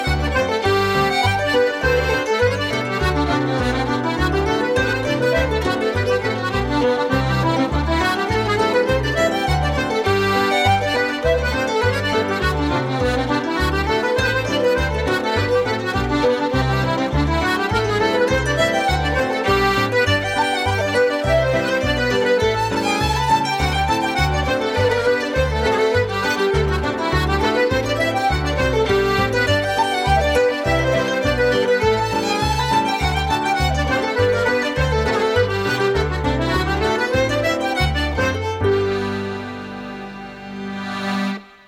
Accordion
With guest musicians
Piano
Harp
Fiddle
Irish traditional music
Reels